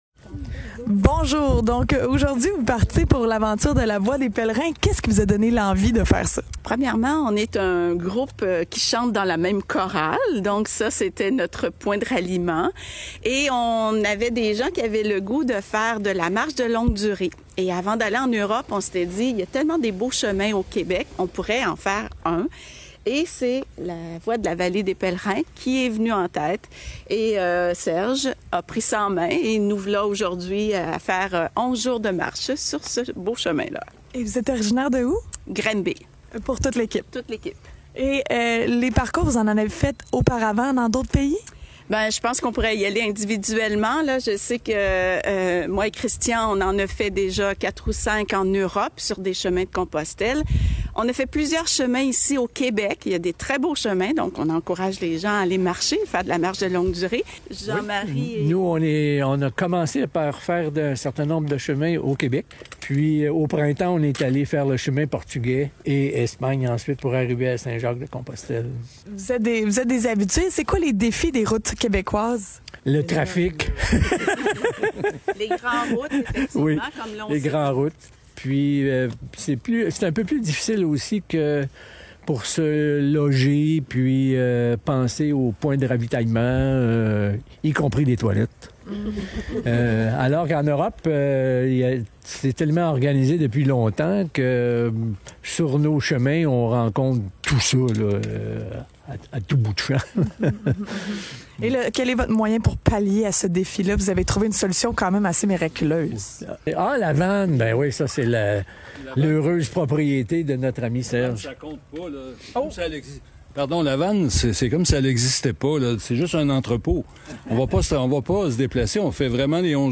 Voici la courte discussion que nous avons eu avec le groupe ce matin :